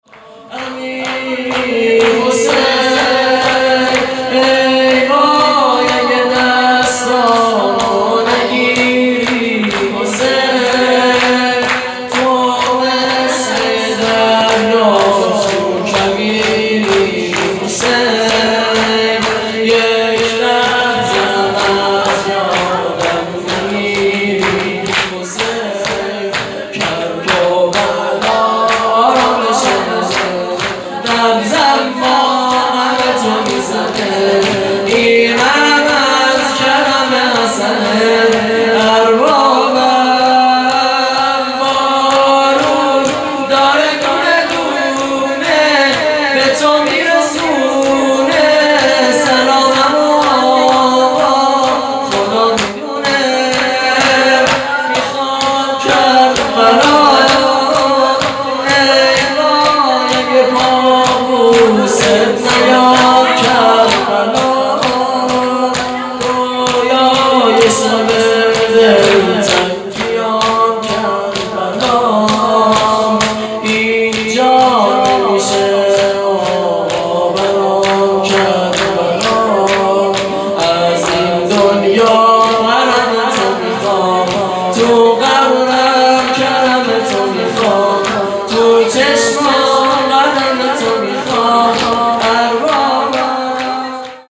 خیمه گاه - شجره طیبه صالحین - امیری حسین - هیئت هفتگی